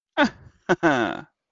dirty laught
a-haha.mp3